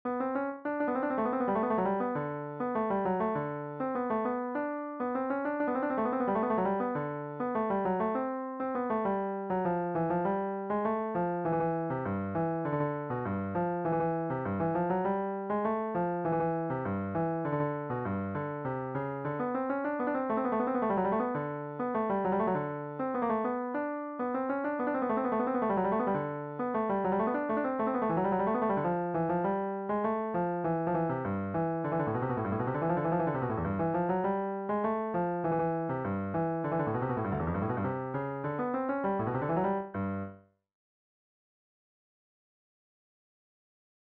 DIGITAL SHEET MUSIC - CELLO SOLO
Cello Melody Only, Traditional Fiddle Tune